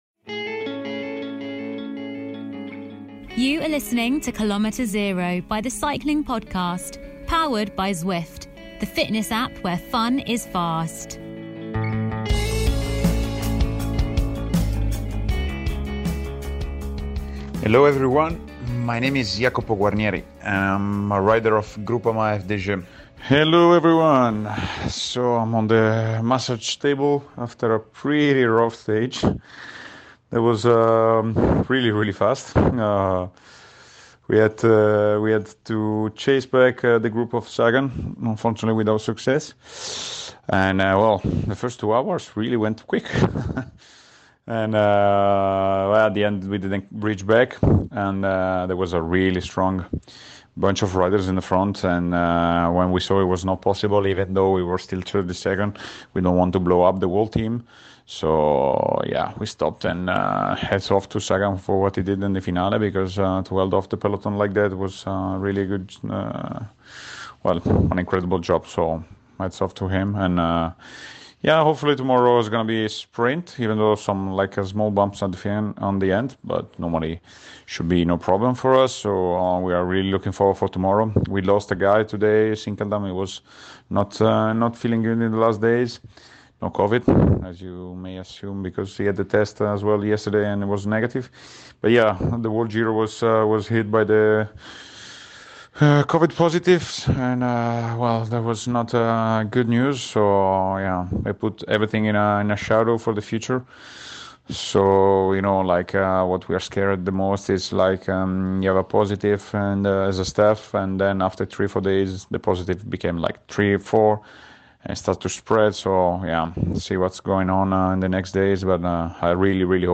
recapping a day's racing while having his post-race massage